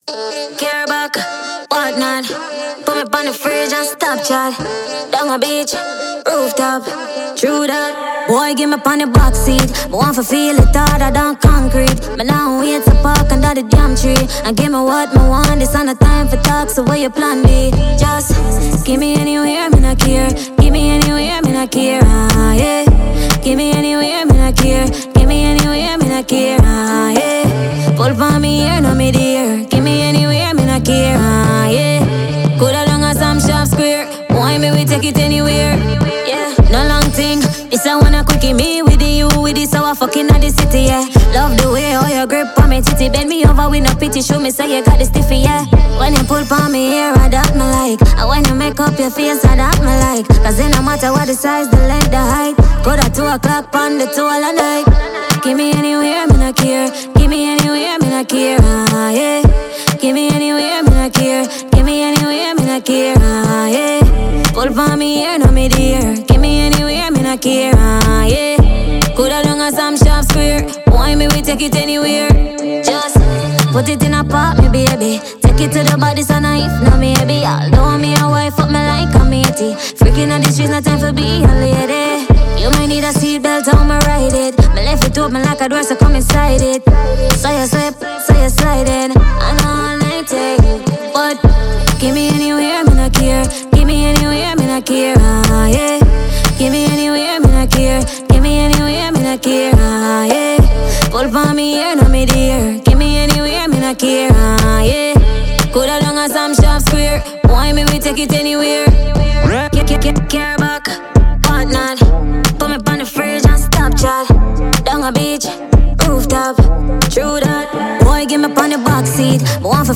Jamaican dancehall diva